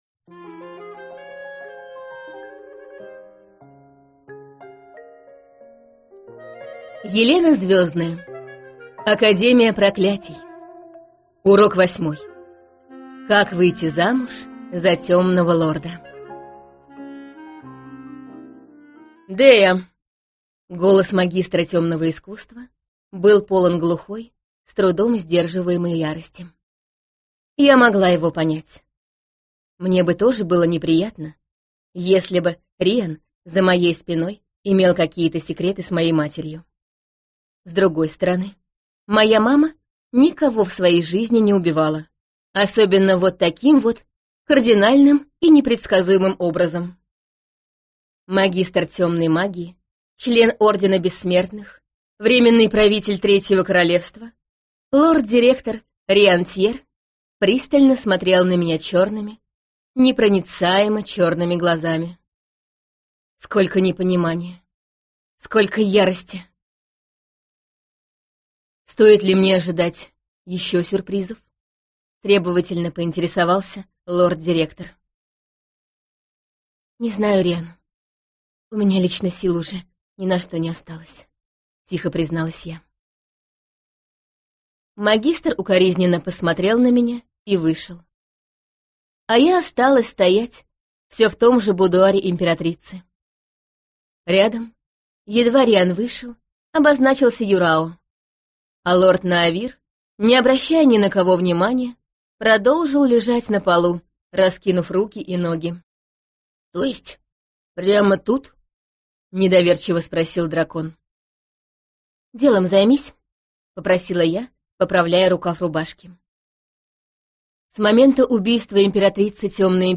Аудиокнига Урок восьмой: Как выйти замуж за темного лорда - купить, скачать и слушать онлайн | КнигоПоиск